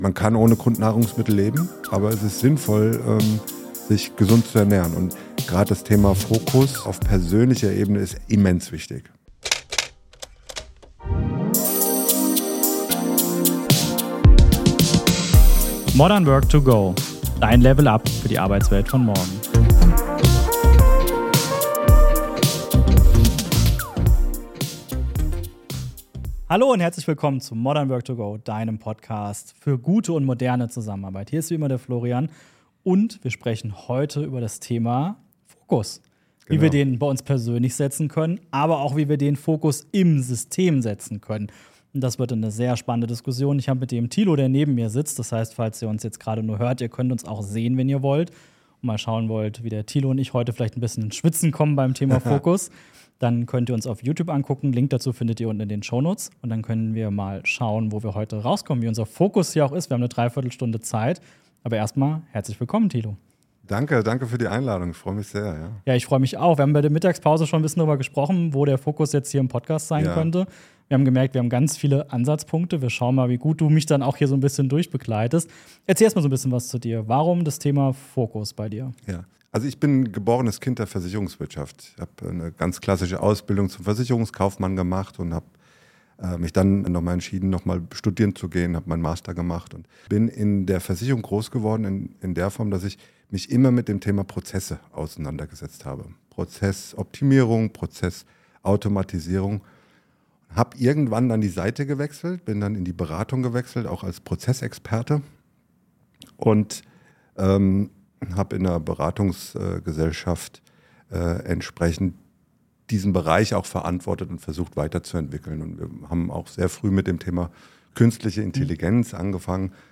#96 Warum so vielen Organisationen der Fokus fehlt - Interview